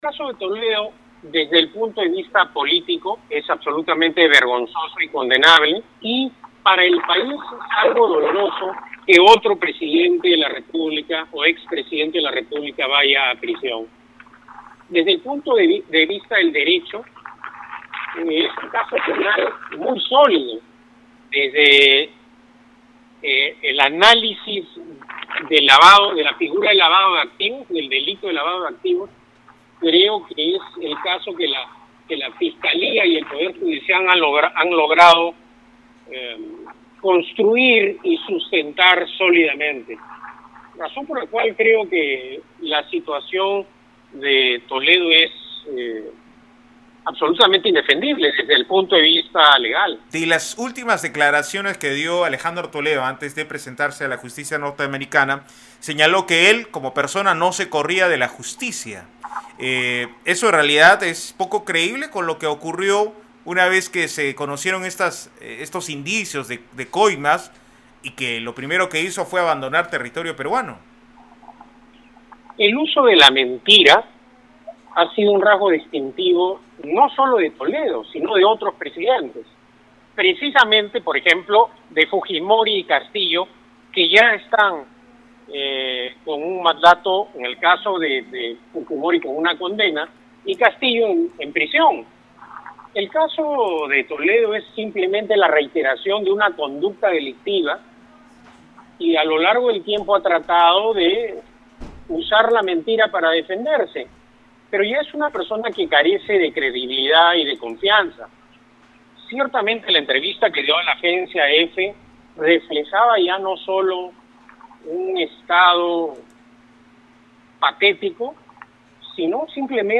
En conversación con Radio Uno, Cateriano expresó que «desde el punto de vista del derecho, es un caso penal muy sólido» pues «desde el análisis de la figura del delito de lavado de activos es el caso que Fiscalía y Poder Judicial han logrado construir y sustentar, razón por la cual la situación de Toledo es absolutamente indefendible desde el punto de vista legal».